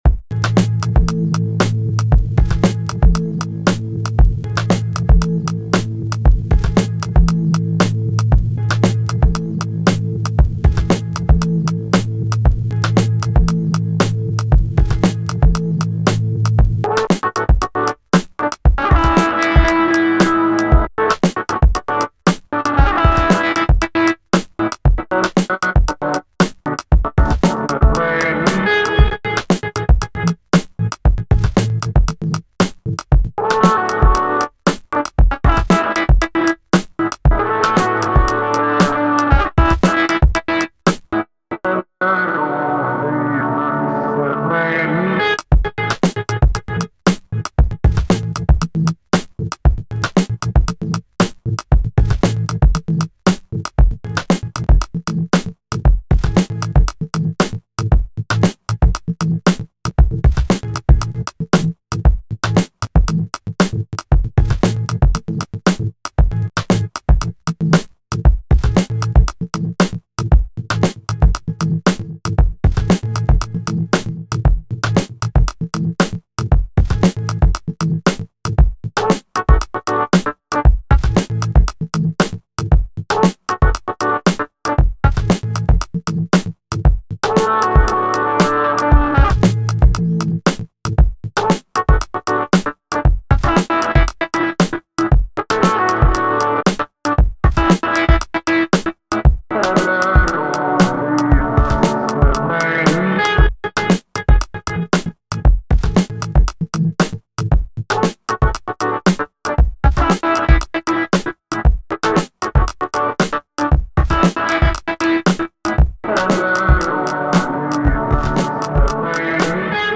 I like these ones, smooth and chill